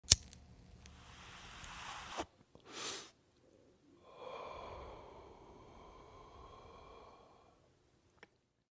smoke inhale
lighter-inhale-exhale-smoke-sound-effects.mp3